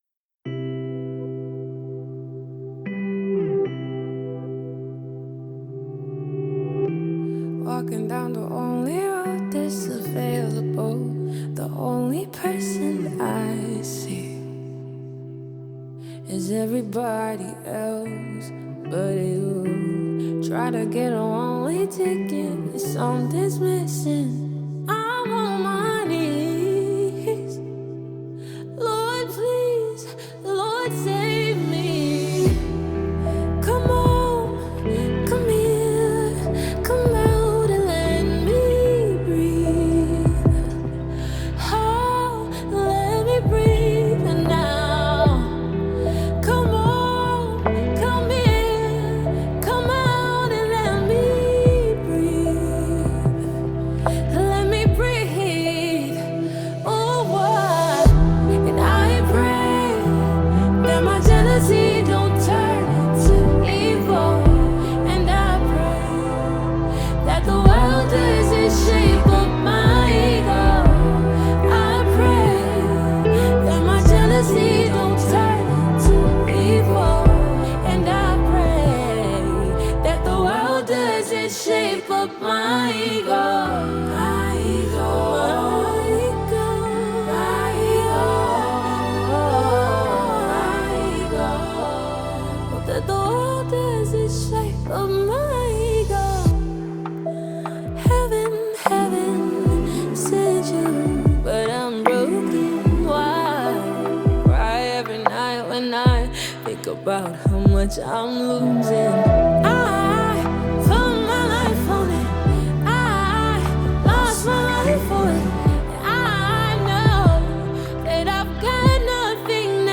Blending Afrobeats rhythm with cinematic intensity
is a high-energy anthem